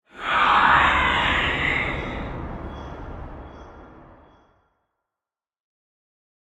shriek2.ogg